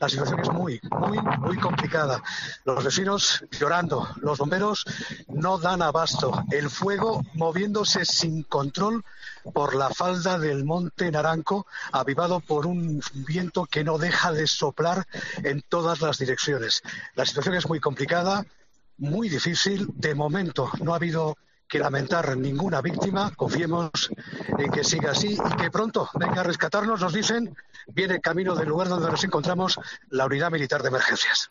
El fuerte viento ahogaba las palabras de nuestro compañero y hacía angustiosa esa conexión.